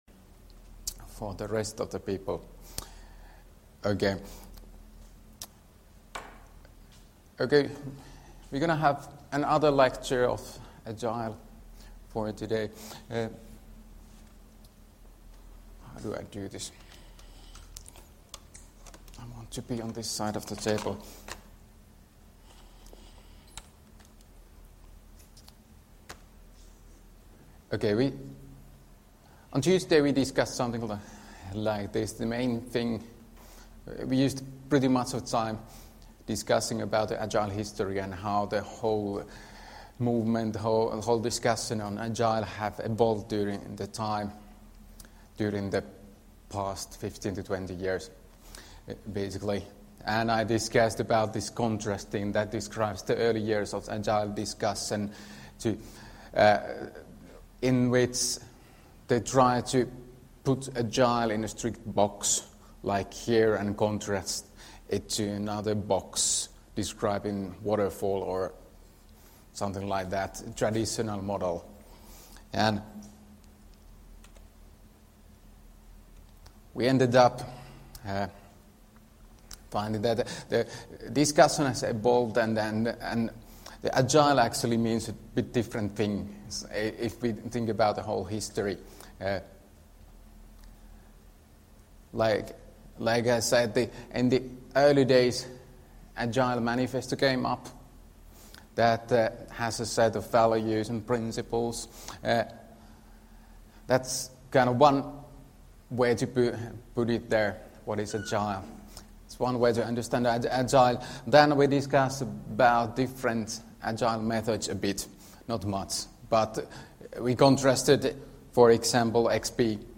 Luento 1.2.2018 — Moniviestin